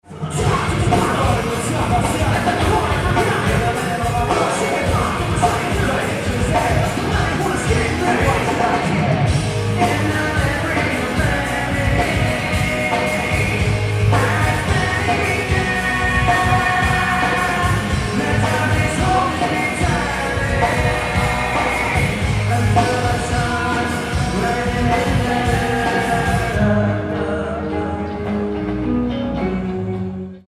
Venue:Le Zénith
Venue Type:Music/Concert Hall
Beat Intro; Ext. Bridge
Source 1: Audio - AUD (MD: AT822 > Aiwa AM-F65)